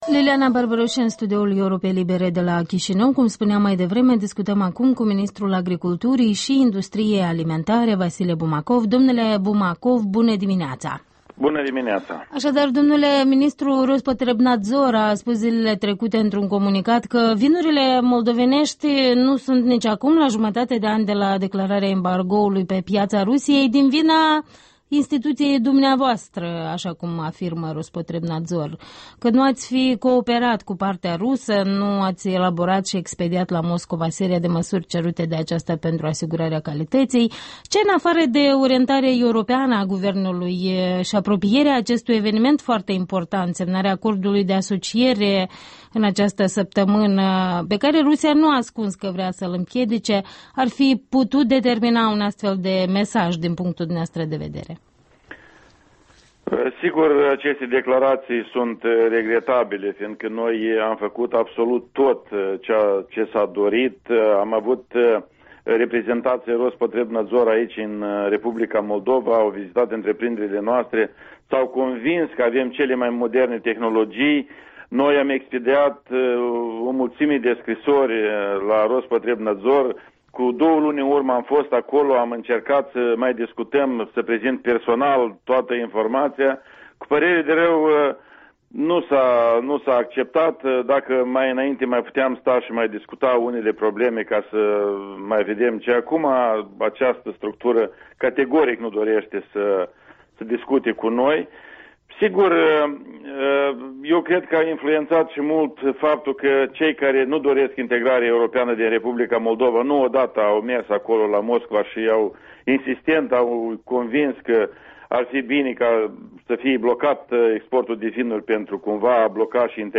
Interviul dimineții cu ministrul Agriculturii, Vasile Bumacov.